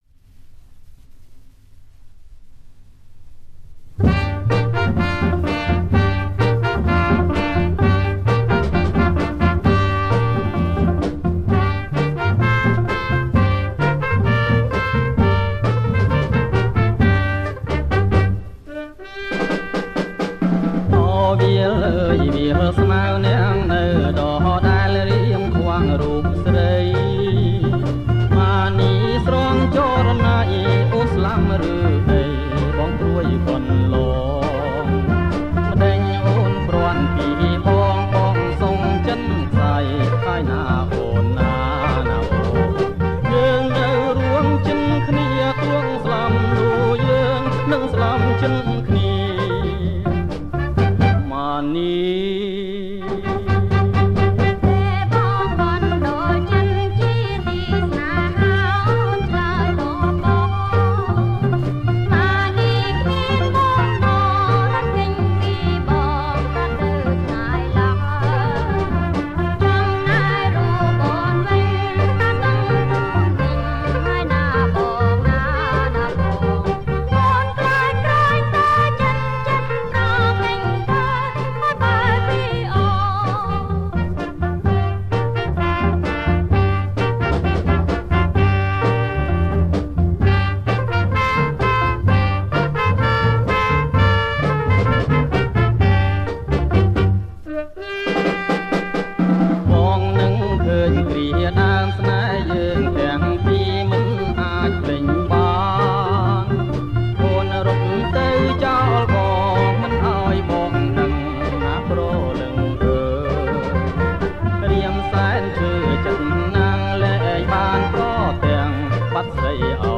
• ប្រគំជាចង្វាក់ Pasodoble